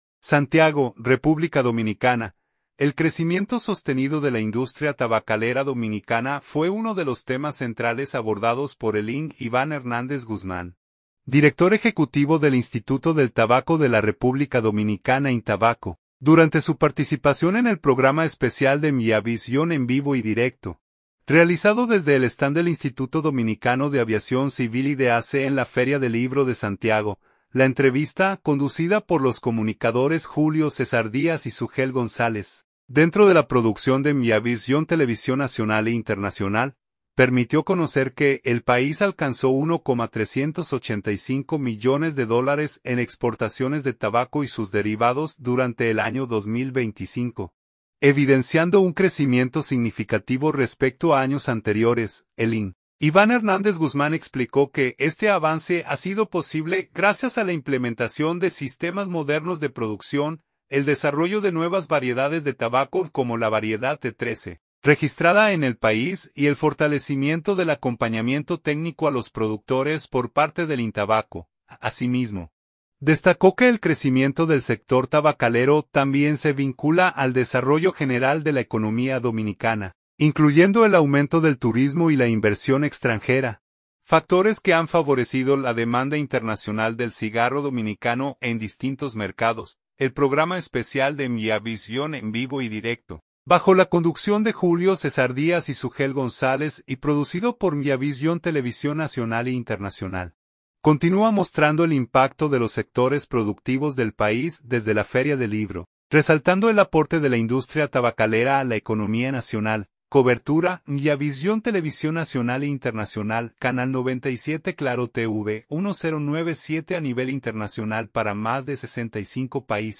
SANTIAGO, REPÚBLICA DOMINICANA. — El crecimiento sostenido de la industria tabacalera dominicana fue uno de los temas centrales abordados por el Ing. Iván Hernández Guzmán, Director Ejecutivo del Instituto del Tabaco de la República Dominicana (INTABACO), durante su participación en el Programa Especial de Miavisión “En Vivo y Directo”, realizado desde el stand del Instituto Dominicano de Aviación Civil (IDAC) en La Feria del Libro de Santiago.